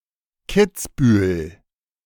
Kitzbühel (German: [ˈkɪtsbyːl]
De-Kitzbühel2.ogg.mp3